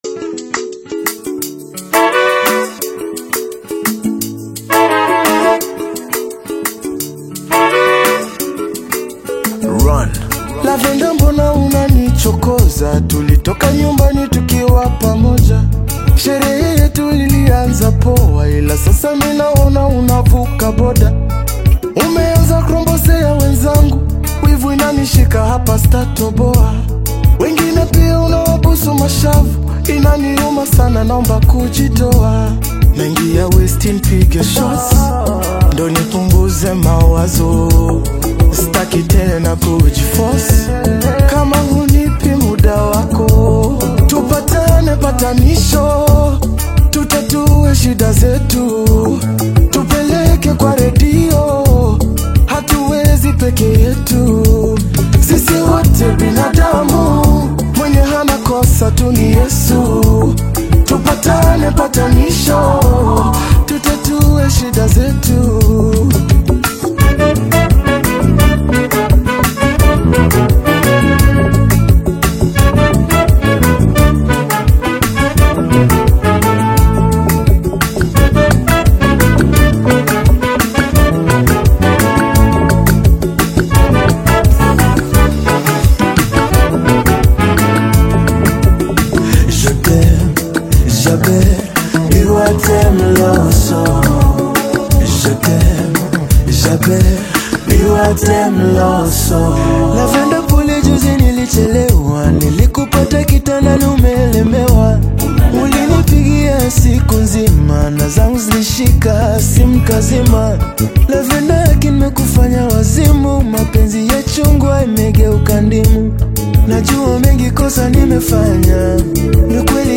Afro-fusion single